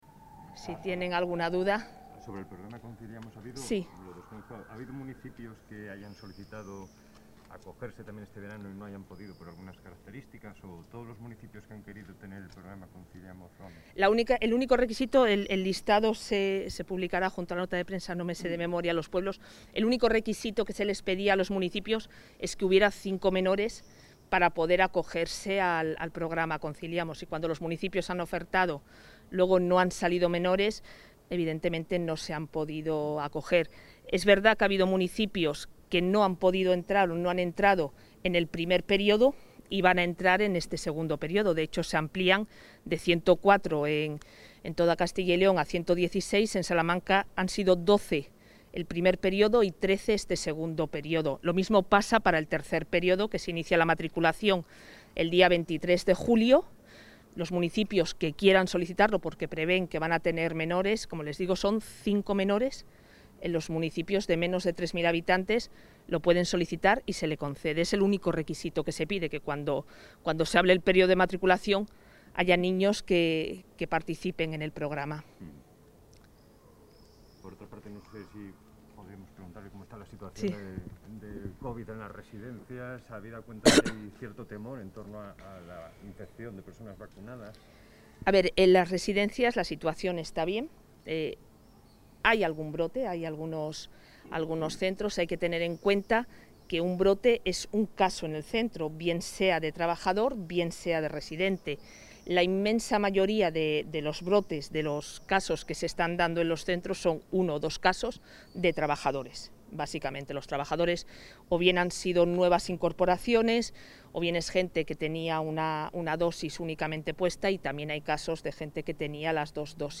Intervención consejera.